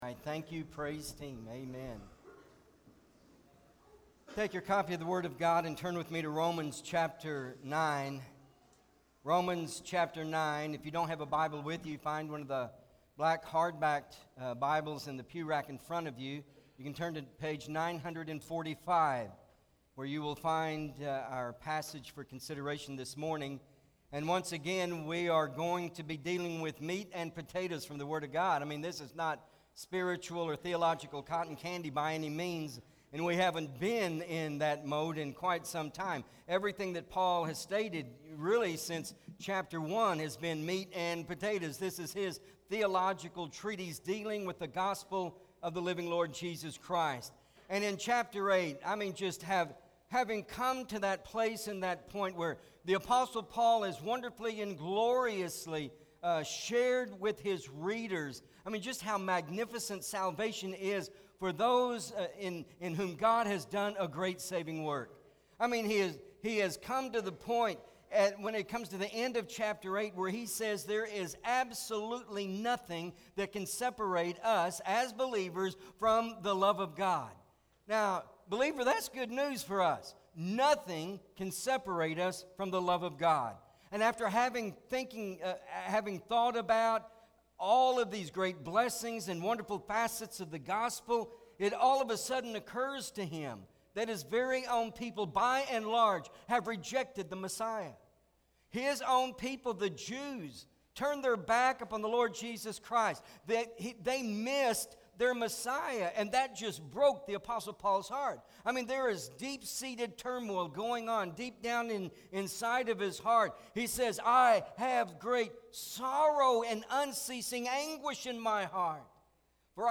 Pt.1 MP3 SUBSCRIBE on iTunes(Podcast) Notes Sermons in this Series Romans 9:19-26 Not Ashamed!